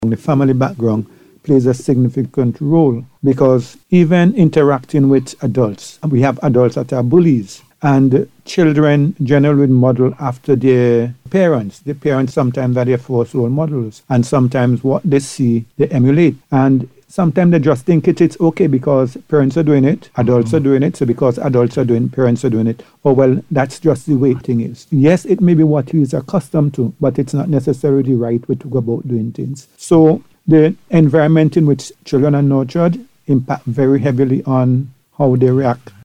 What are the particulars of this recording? speaking during the Police On The Beat Programme.